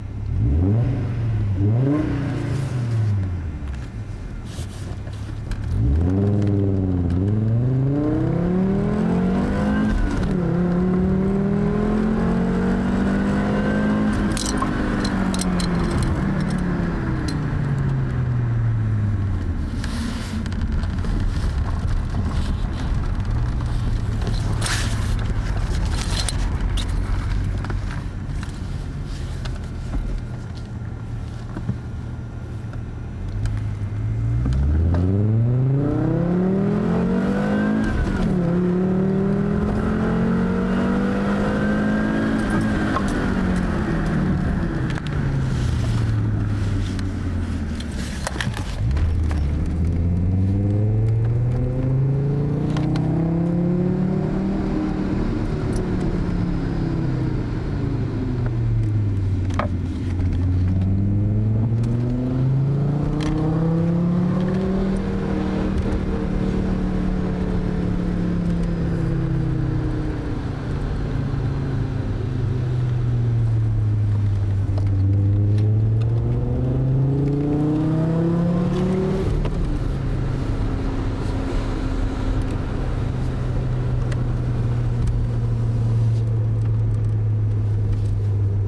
Die Boxen voll aufdrehen, ist ein wenig leise Aufgenommen (Aufnahmepegel) Bei geschlossenen Fenstern:
Leider war die Kamera falsch platziert (Auf Beifahrersitz, Mic nach vorne, offenes Fenster) so das die Windgeräusche bald lauter waren als der Sound von hinten
20V-Sound-innen-auf-2.mp3